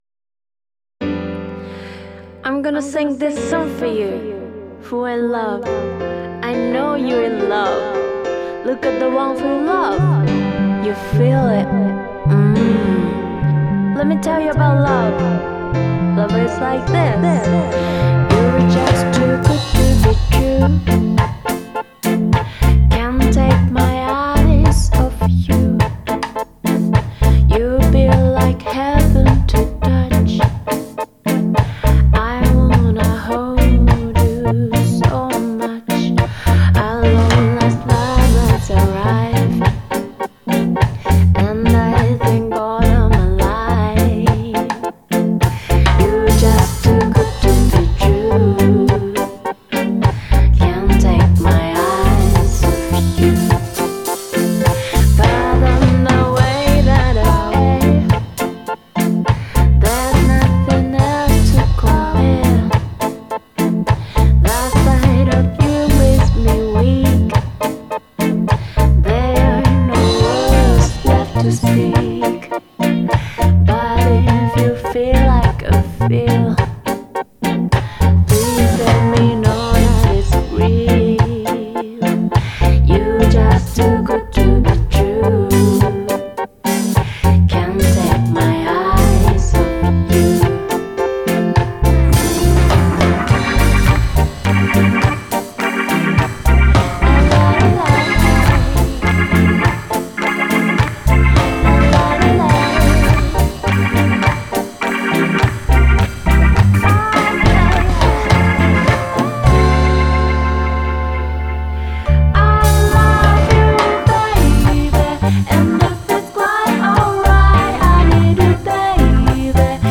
ジャンル(スタイル) JAPANESE REGGAE